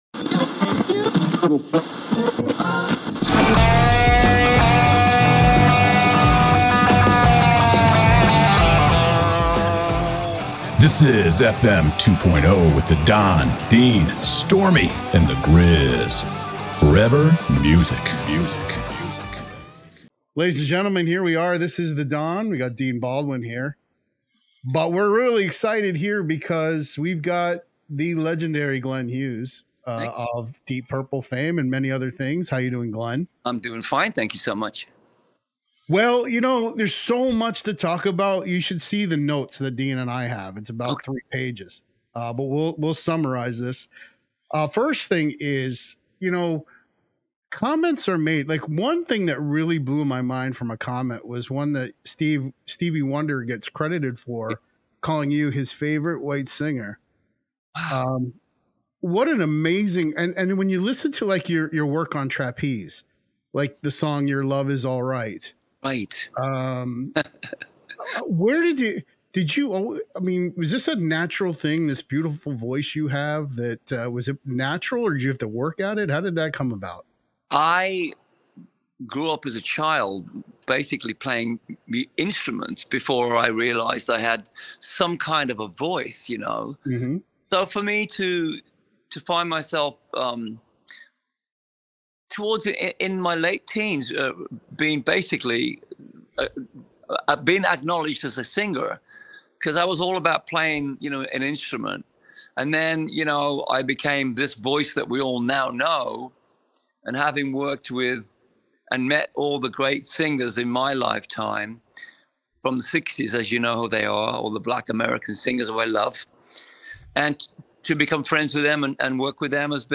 Glenn joined us and we discussed his amazing vocals which started in Trapeze to his time with Deep Purple. Glenn also shared stories about Stevie Wonder and being David Bowie's roommate. We discuss songs from his current set list and Glenn shares amazing stories.